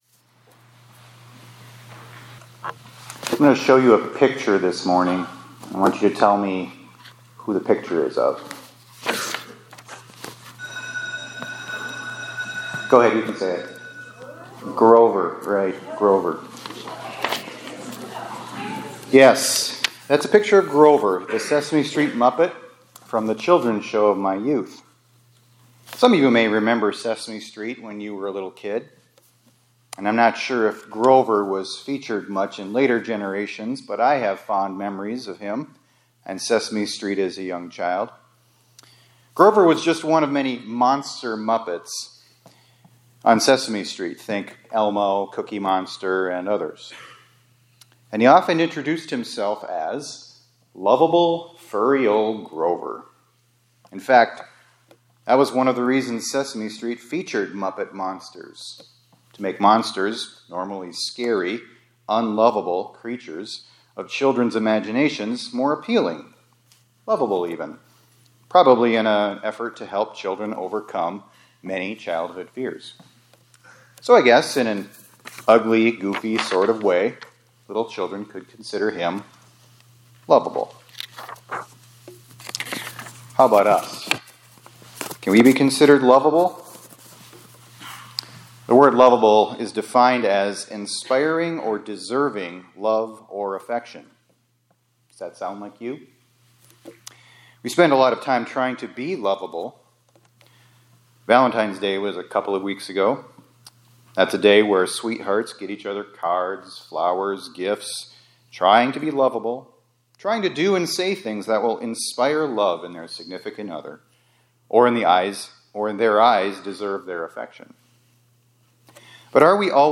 2025-02-28 ILC Chapel — From Unlovable to Beloved